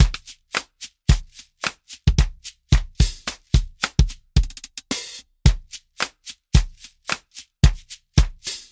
描述：嘻哈或流行音乐
Tag: 109 bpm Rap Loops Drum Loops 1.47 MB wav Key : Unknown Logic Pro